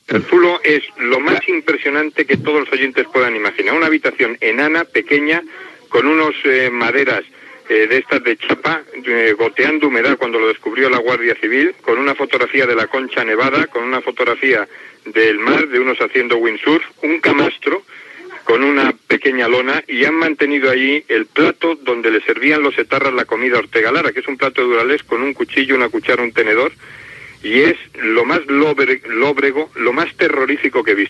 Connexió amb el lloc on ha estat alliberat el funcionari de presons José Antonio Ortega Lara, segrestat per ETA.
Informatiu